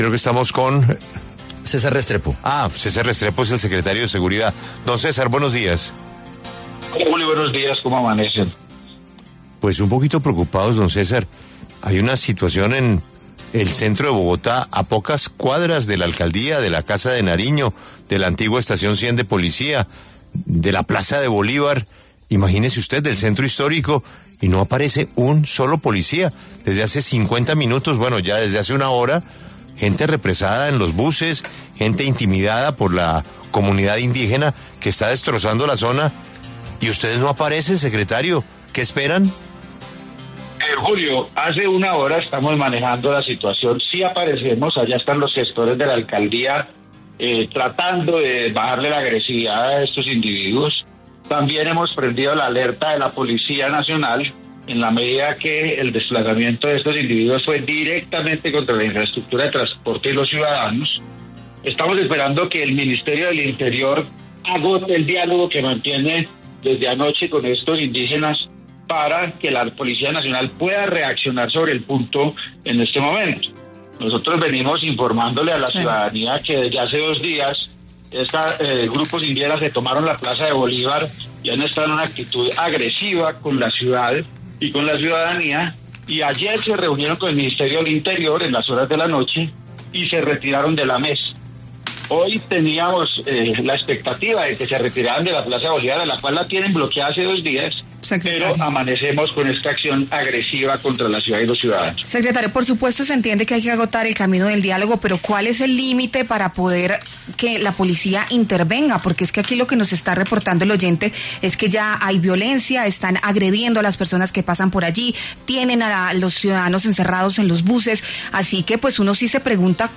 César Restrepo, secretario de Seguridad de Bogotá, habló en La W sobre las recientes afectaciones que están generando comunidades indígenas en el centro de Bogotá por protestas.